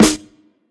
ERWT_SNR.wav